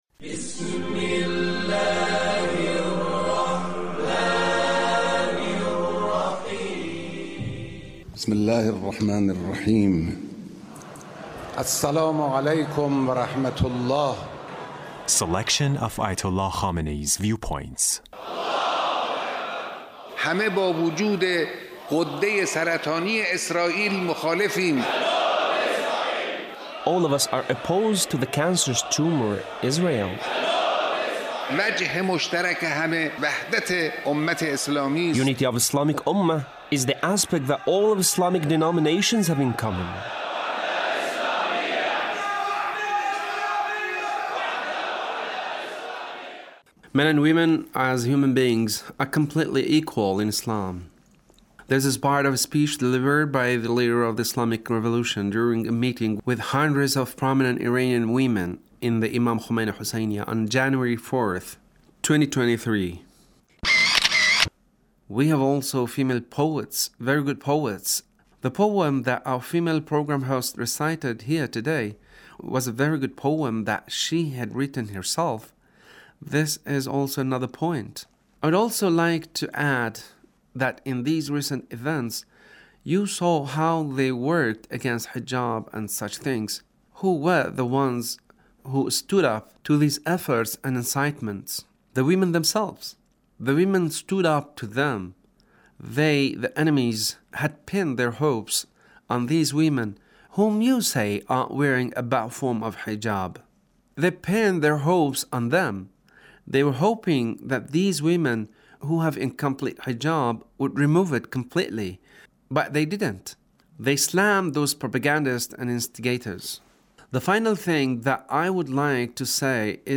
Leader's Speech (1641)
Leader's Speech meeting with ladies